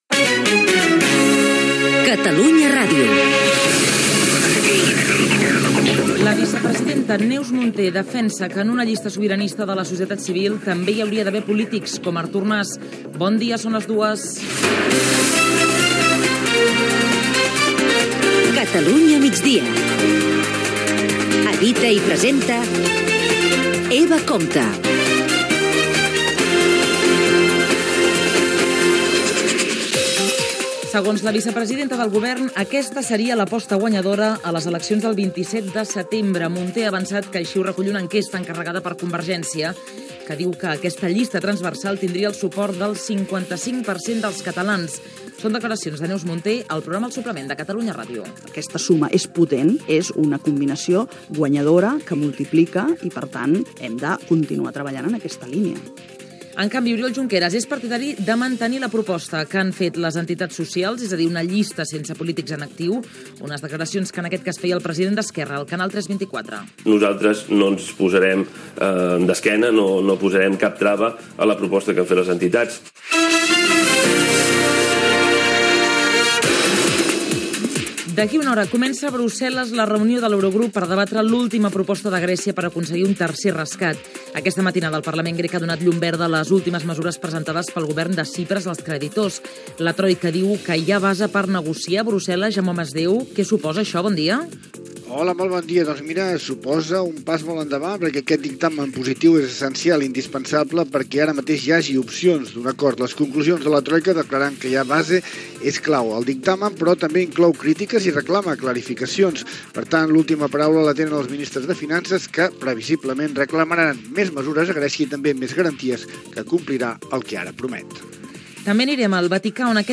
Hora, careta del programa, resum informatiu, El temps, el trànsit, indicatiu, data i hora
Informatiu